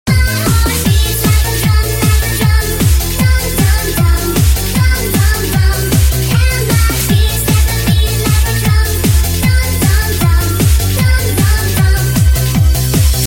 tiny flash warning too!!) beep sound effects free download